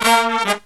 Index of /90_sSampleCDs/USB Soundscan vol.29 - Killer Brass Riffs [AKAI] 1CD/Partition B/09-108SL SB5